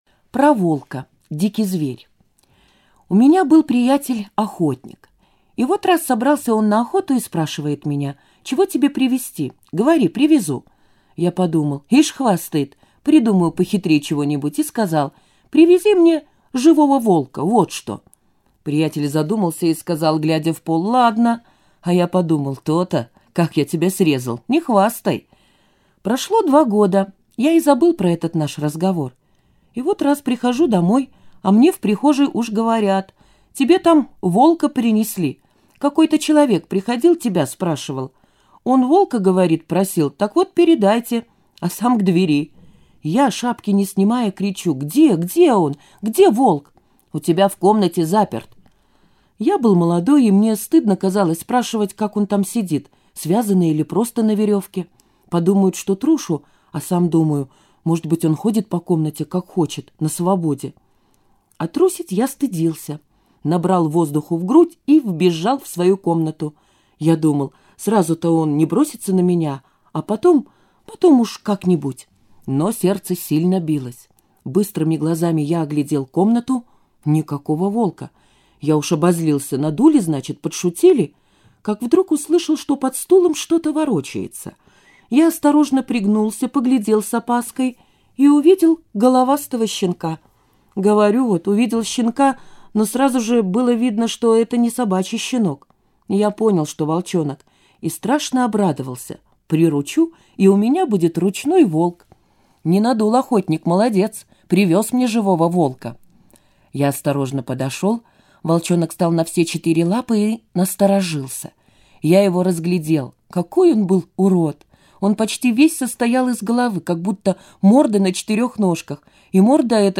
Про волка - аудио рассказ Житкова - слушать онлайн